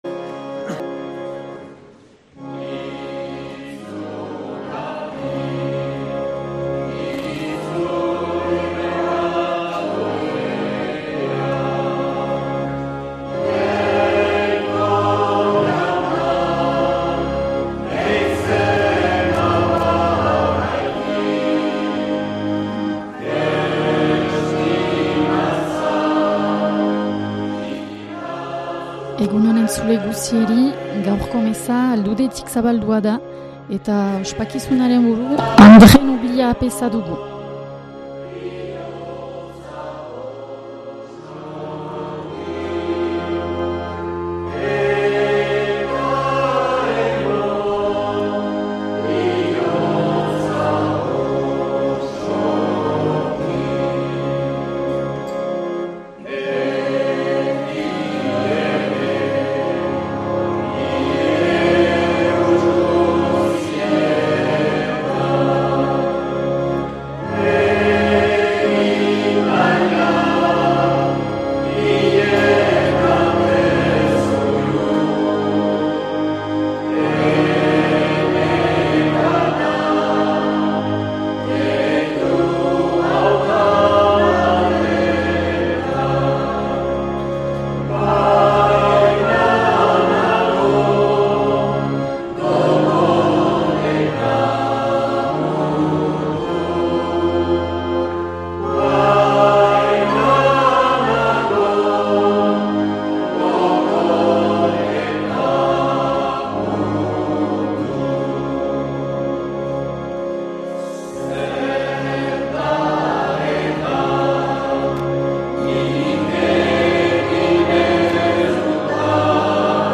2026-03-15 Garizumako 4. Igandea A - Aldude